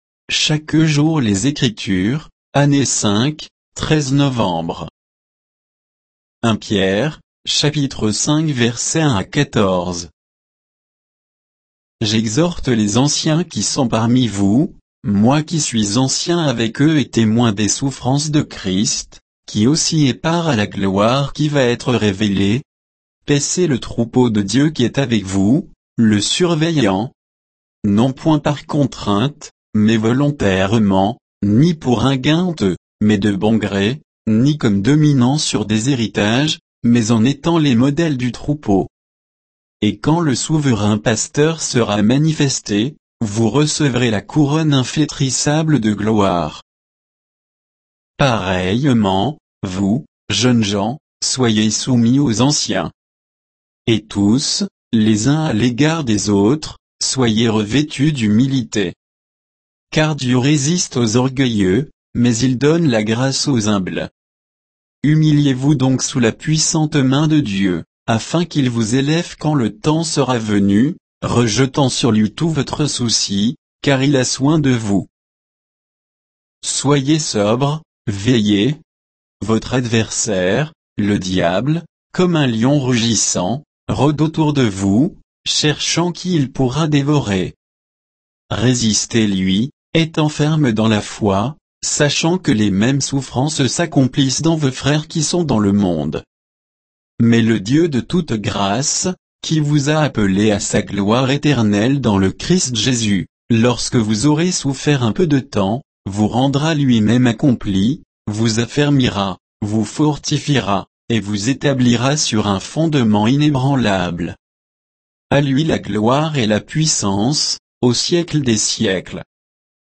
Méditation quoditienne de Chaque jour les Écritures sur 1 Pierre 5, 1 à 14